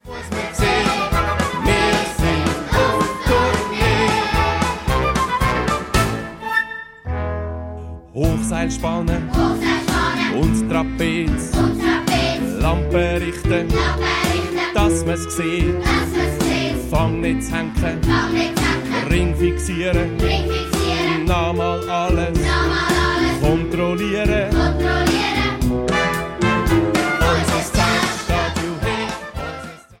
Mundartlieder für Chinderchile